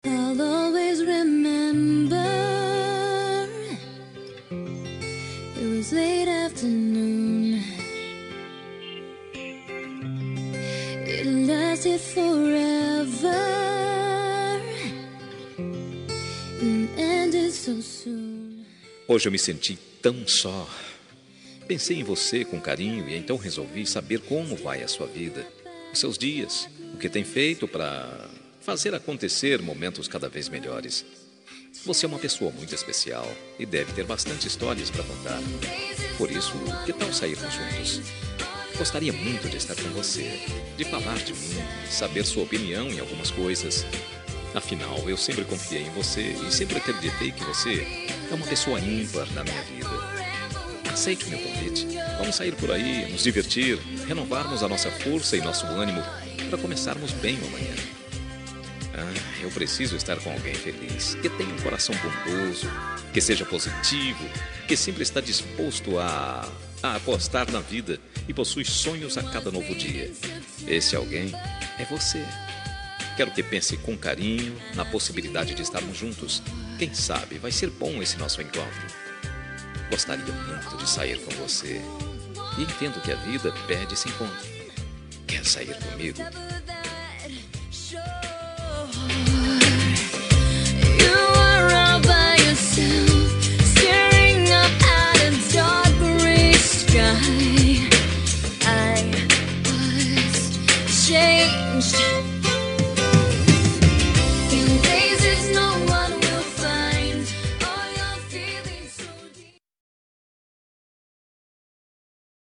Telemensagem de Pedido – Voz Masculina – Cód: 041712 -Quer sair comigo